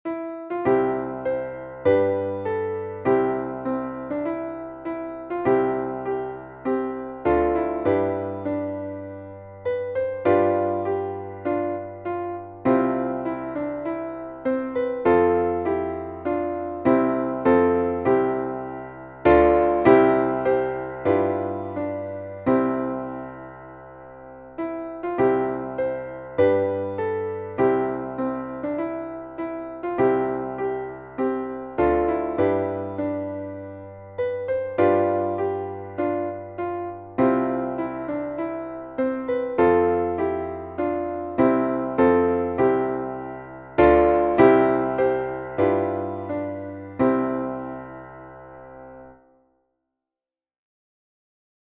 Haendel, G. F. Genere: Religiose Text by Nahum Tate While shepherds watch'd their flocks by night, All seated on the ground, The angel of the Lord came down, And glory shone around.